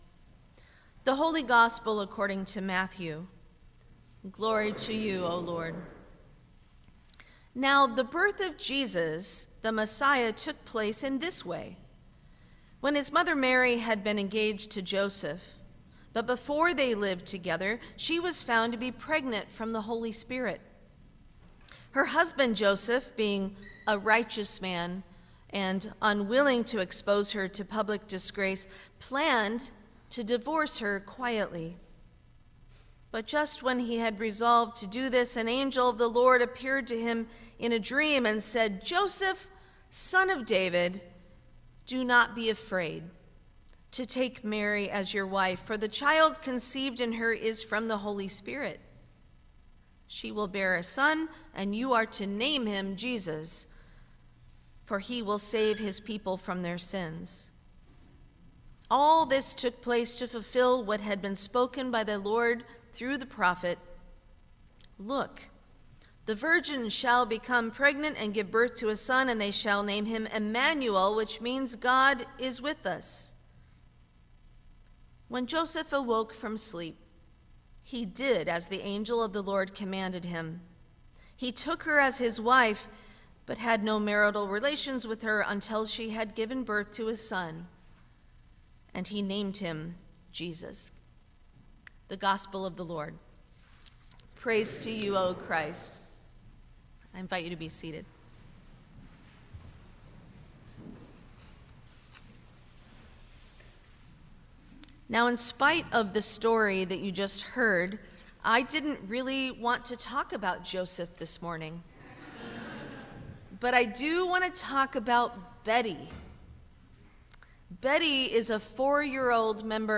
Sermon for the Fourth Sunday of Advent 2025